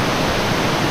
ta4_jetpack.ogg